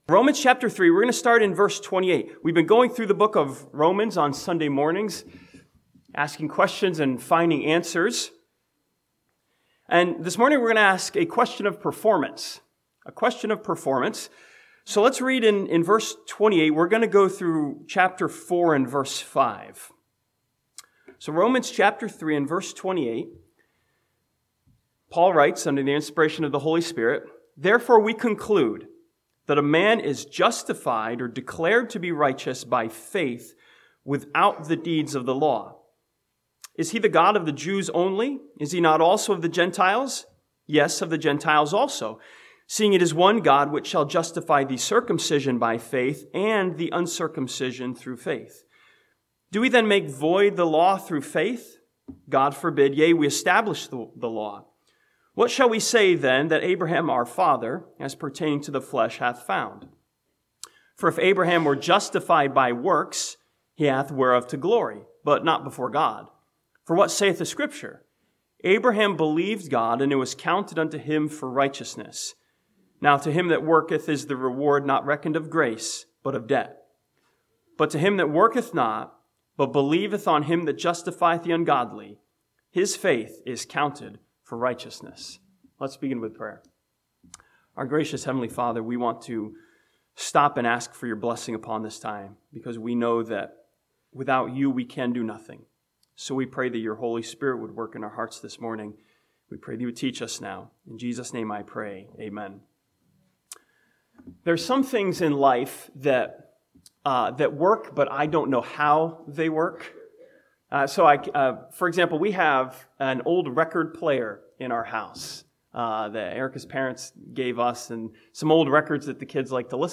This sermon from Romans chapters 3 and 4 asks a question of performance: how does faith actually work?